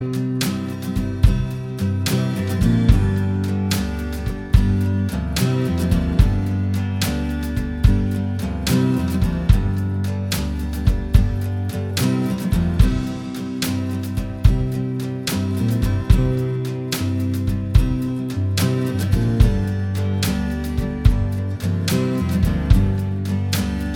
Minus Slide Guitar Rock 3:16 Buy £1.50